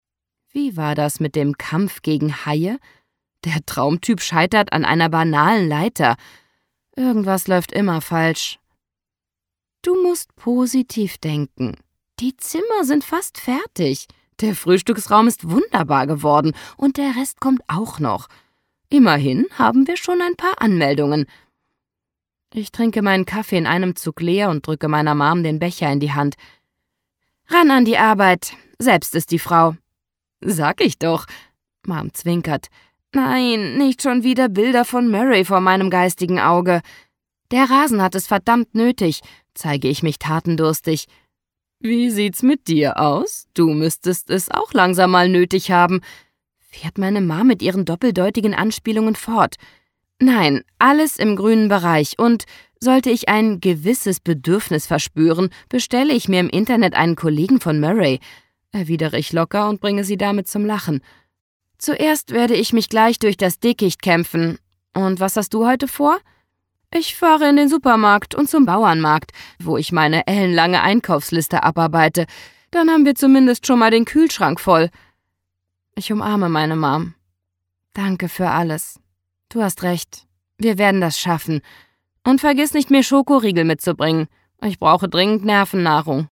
Infos zum Hörbuch
Liebesromane, Fantasy Romance